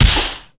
SnootGame/game/audio/effects/slapstickPunch.ogg at e9569a83c6c0aa051ed8cf43c3806be0740ff437
slapstickPunch.ogg